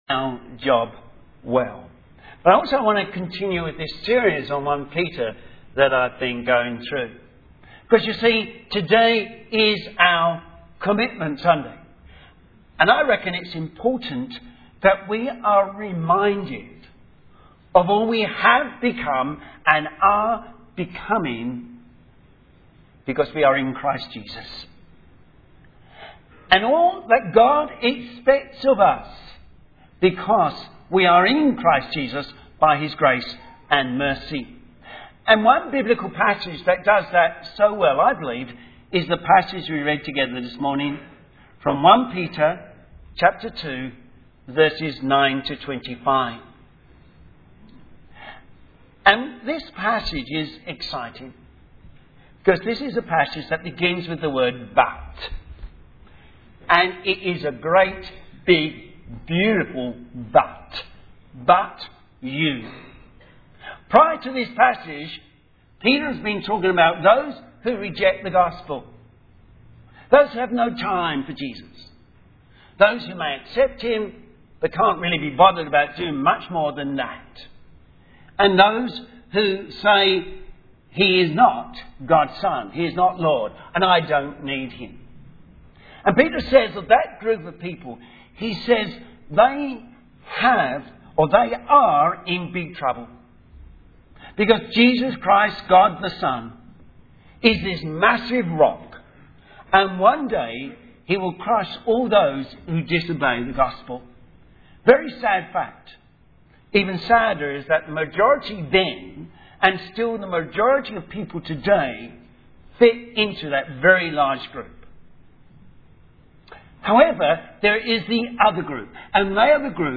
Sermon
First Peter Series #8 Doing our job well 1 Peter 2:9-25 Synopsis This sermon was preached on the first Sunday of a new year as a Commitment Sunday Service. It reminds everyone about who we are in Christ and what He now expects from us.